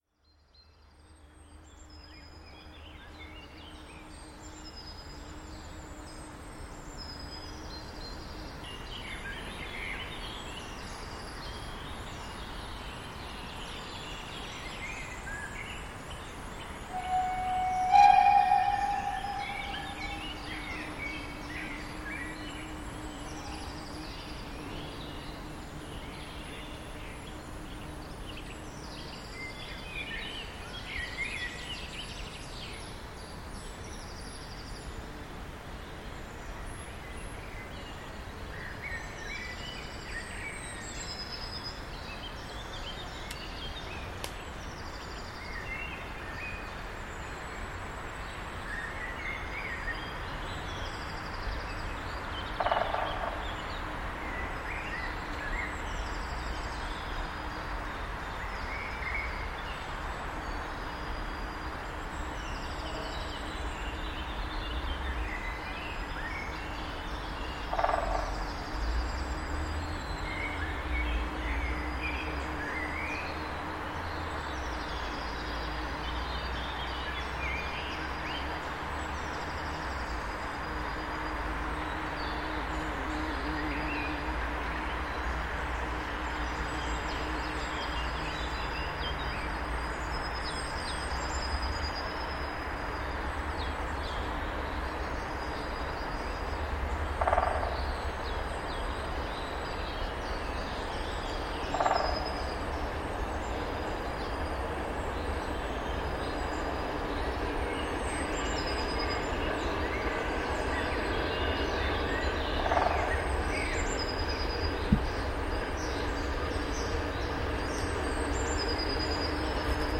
Ob prinzipiell diese Bauart des ROSE, also die darin eingebaute Zentrifuge, oder ob nur die geringere Größe der Lokomotiven der Grund dafür ist, weiß ich zwar nicht, aber der WEM-Funkenfänger scheint mir wesentlich leiser und verhaltener zu klingen als der normale Kobel auf der I K. Hört einfach mal selbst an...:
99 3317  mit erstem Zug Bad Muskau→Weißwasser der Veranstaltung zu Ostern 2025, aufgenommen hinter Bad Muskau in der Steigung am Waldrand hinter der letzten Kurve durch den Park-Wald, um 10:51h am Karfreitag, den 18.04.2025.
Die Lok hat einige Schwierigkeiten bei der Bergfahrt, gerät zweimal in so heftiges Schleudern, dass der Zug liegen bleibt und zurückrollen muss.
Als die Lok am Aufnahmegerät vorbeikracht, ist das schon der dritte und erfolgreiche Startversuch. da muss es irgendwelche Probleme mit Sandstreuern gegeben haben...(?).